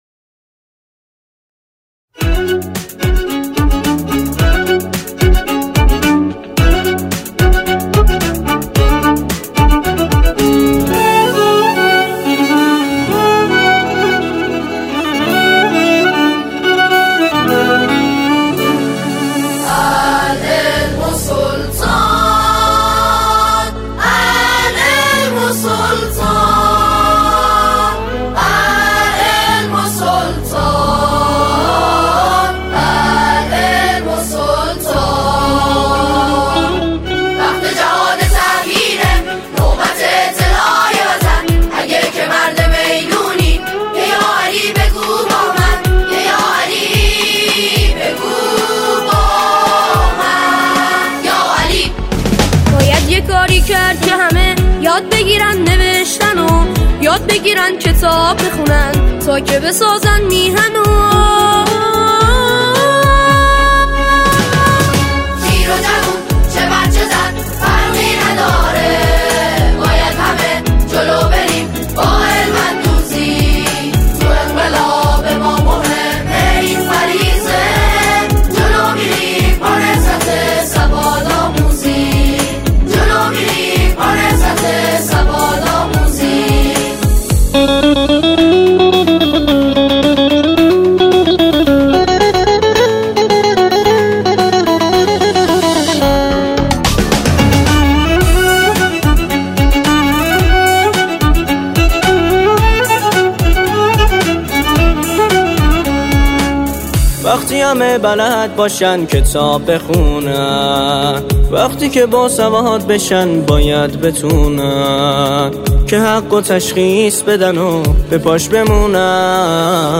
به صورت جمع خوانی به مرحله اجرا درآمده است